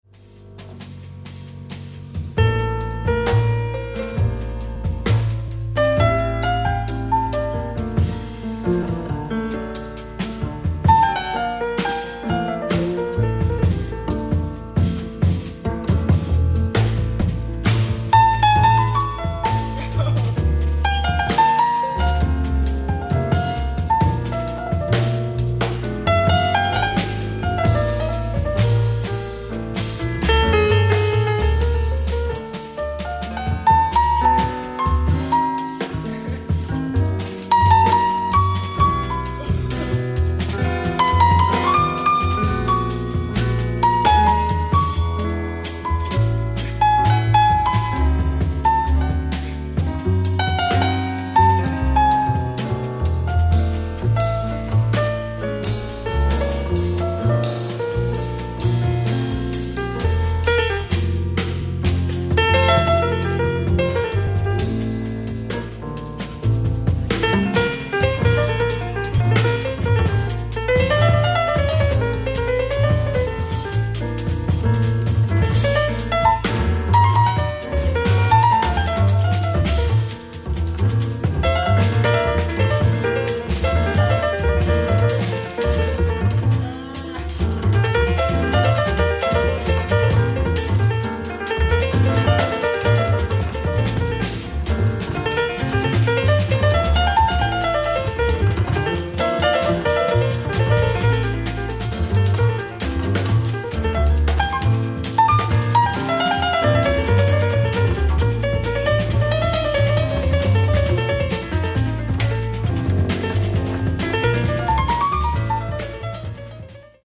アット・ＮＤＲ ジャズ・ワークショップ １００、フンクハウス、ハノーファー、ドイツ 04/18/1974